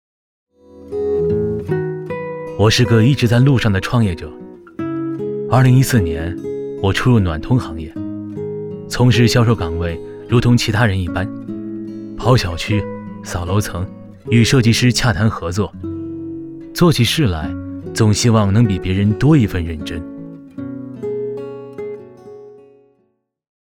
旁白-男19-自述.mp3